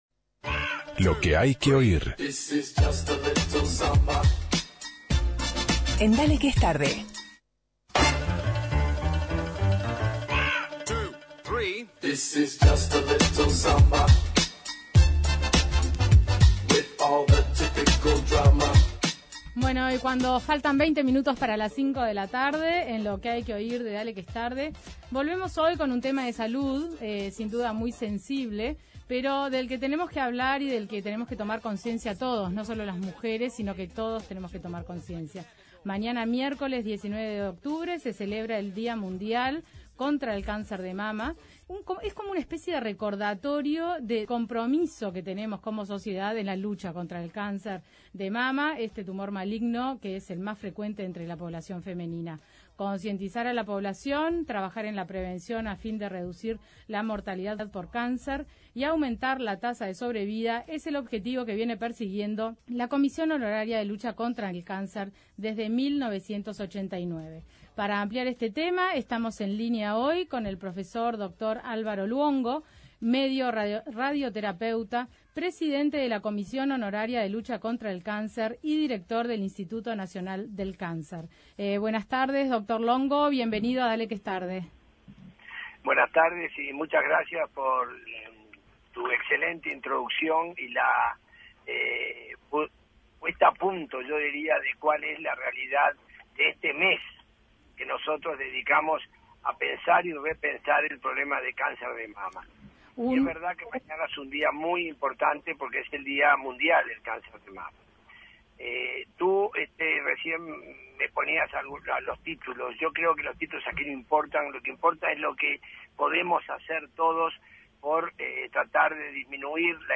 Por esta razón, el martes 18 de octubre, recibimos en Dale Que Es Tarde al Prof Dr. Álvaro Luongo, Presidente de la Comisión Honoraria de Lucha contra el Cáncer y Director del Instituto Nacional del Cáncer (INCA), con quien conversamos de la importancia de seguir generando conciencia en la población con el obejtivo de lograr un diagnóstico oportuno de la enfermedad para reducir la mortalidad y aumentar la tasa de sobrevida. El Dr Luongo adelantó en DQET la innauguración del primer nodo de información digitalizado de mamografías el próximo el lunes 24 de octubre en el Instituto Nacional de Cáncer.